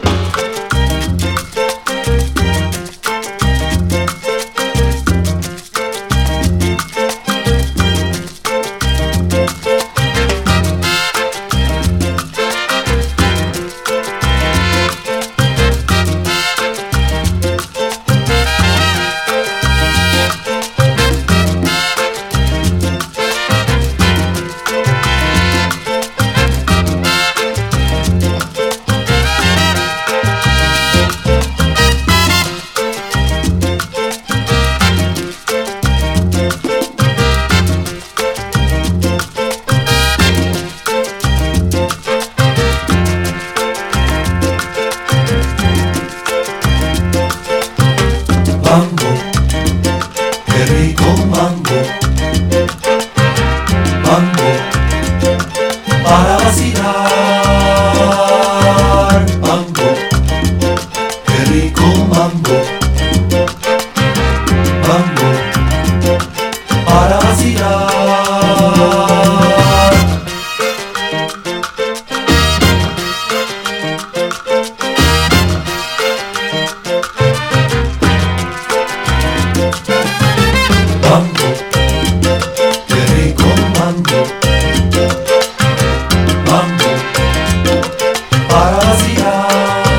ラテン・ジャズ〜サルサ～マンボ～レアグルーヴまで名演ギッシリの2枚組コンピレーション！